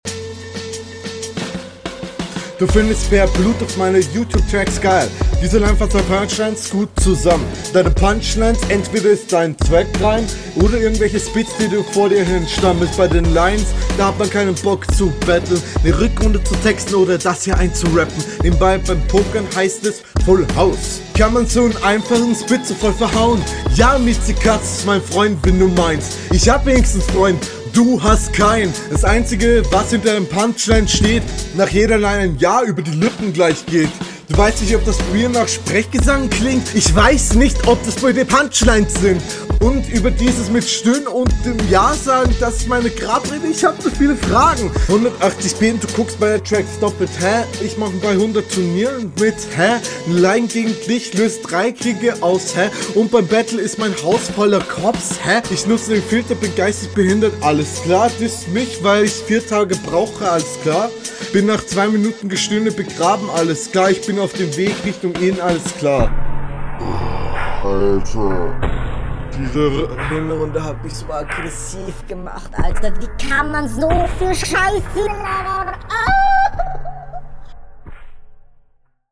Irgendwie kommt es mir so vor als würdest du auf einmal lispeln.
gute atomation hüllkurve und taktgefühl punkt für punkt steigerung zum ersten und zweiten part auch …